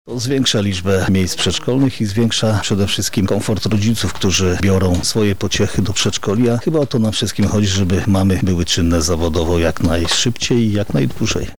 Projekt realizowany jest w ramach Działania 12.1 Edukacja przedszkolna Regionalnego Programu Operacyjnego Województwa Lubelskiego– mówi Marszałek Województwa Lubelskiego Jarosław Stawiarski: